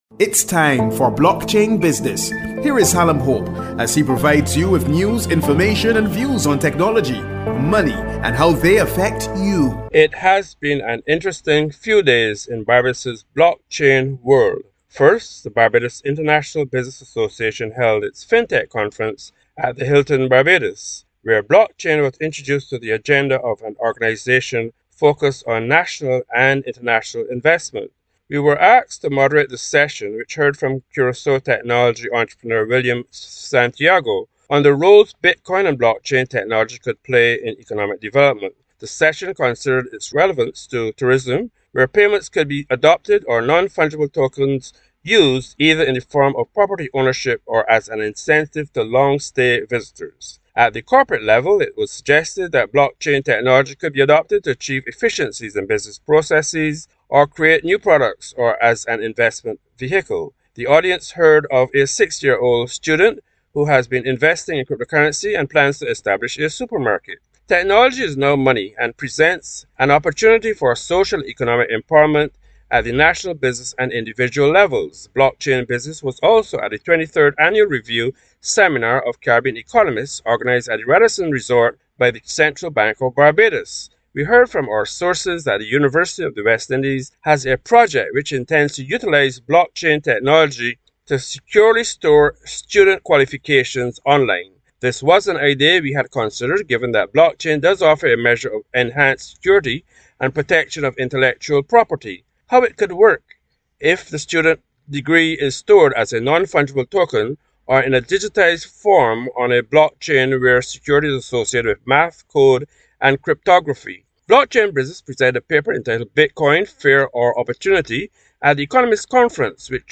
A discussion on Blockchain's role in Economic development